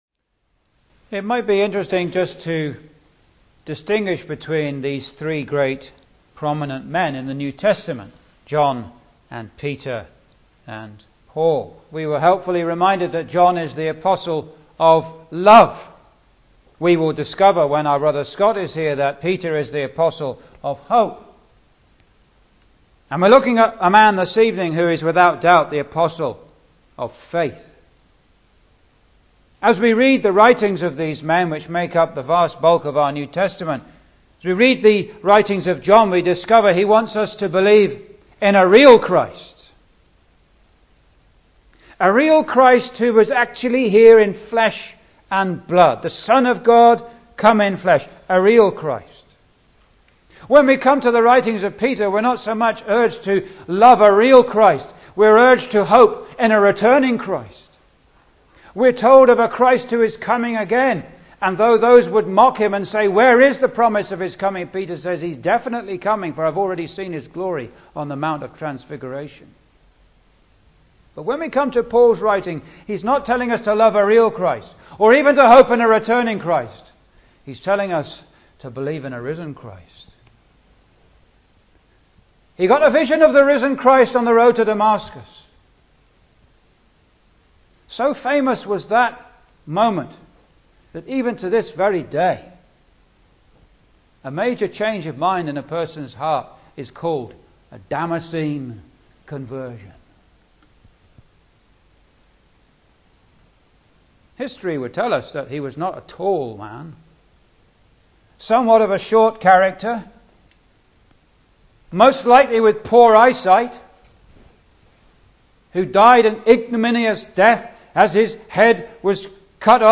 (Message preached 14th May 2015)